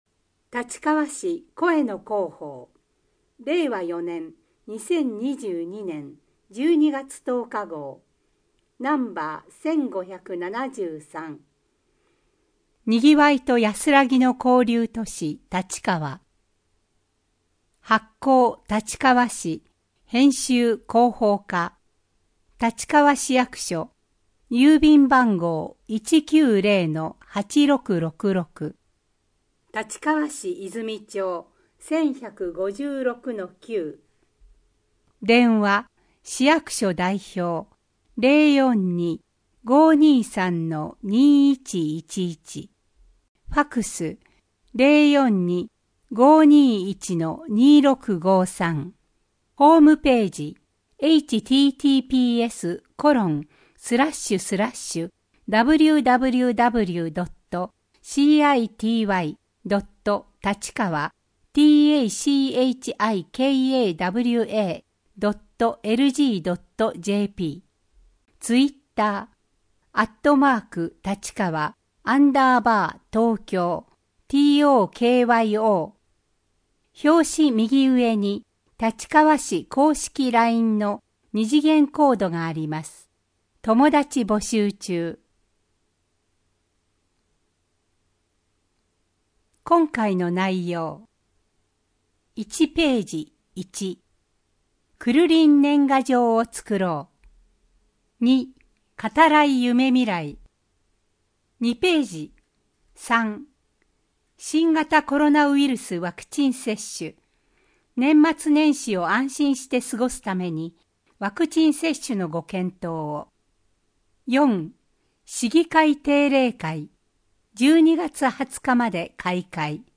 MP3版（声の広報）